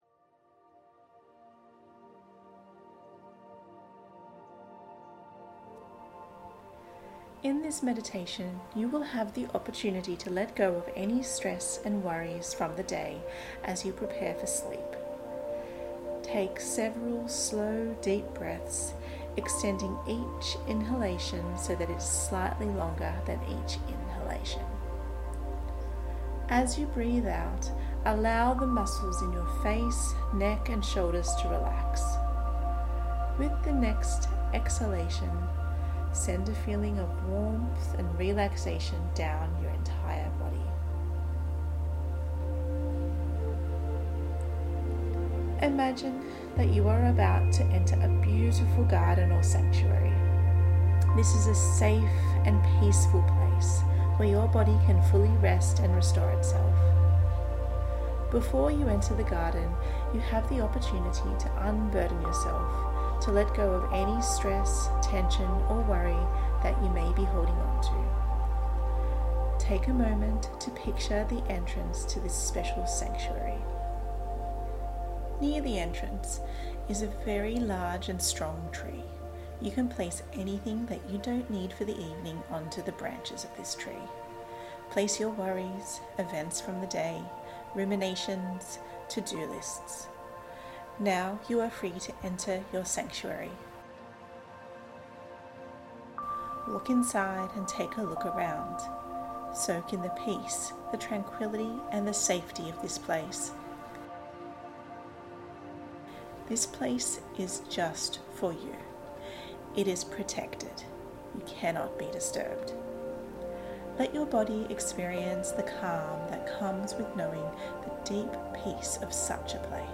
sleep visualisation with music
sleep-visualisation-with-music.mp3